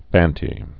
(făntē, fän-)